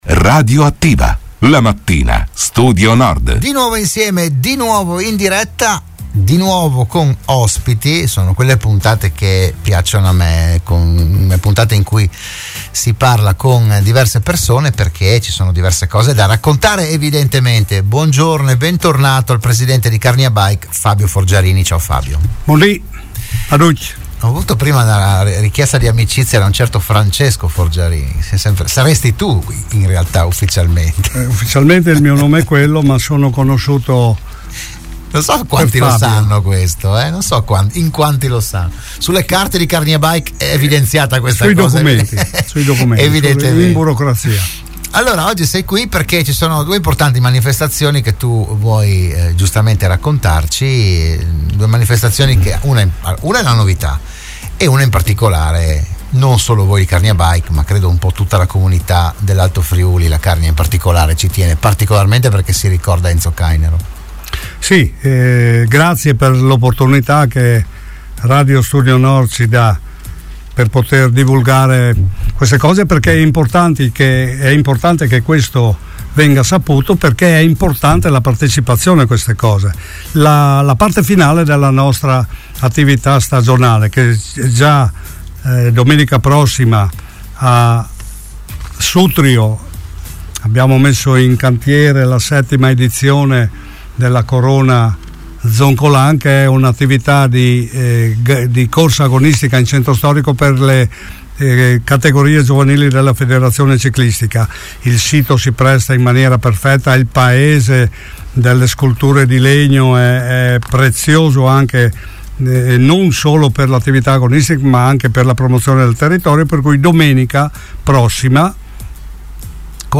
Delle iniziative si è parlato a “RadioAttiva“, la trasmissione di Radio Studio Nord